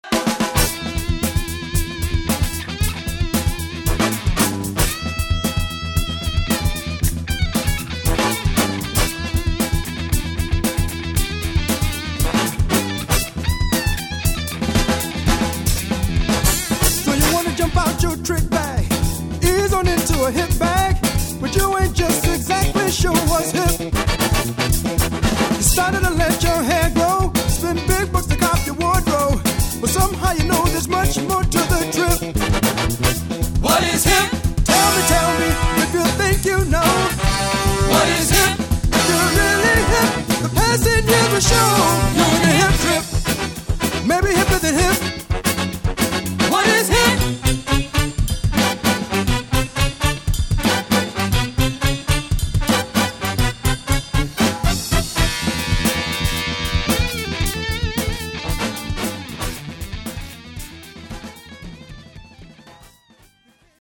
classic soul and funk music from the 60s and 70s